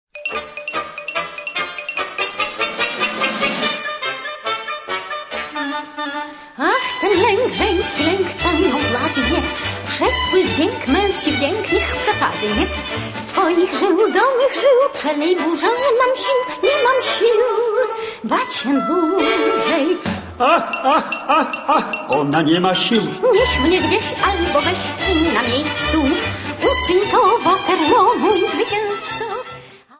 40 Hit Polish Cabaret Songs